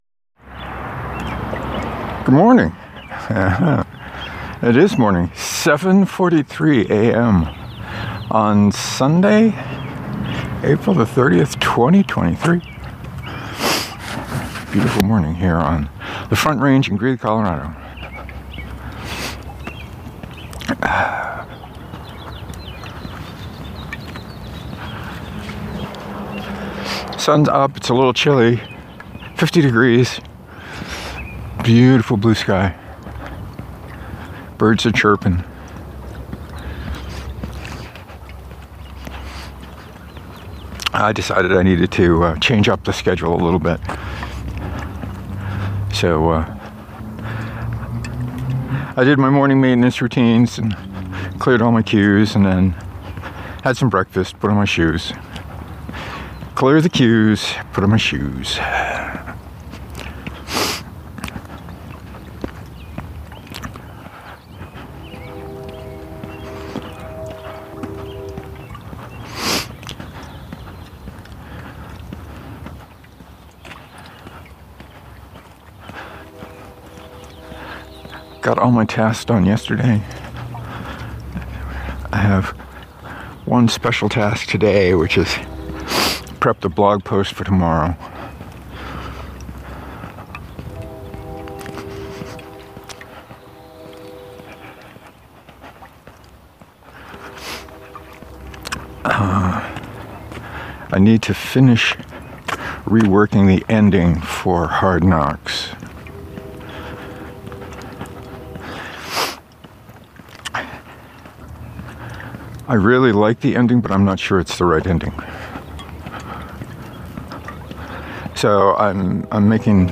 It felt good to be out walking the quiet of the morning, cool breeze on my face. I talked about sour dough, games, and the ever present lilacs.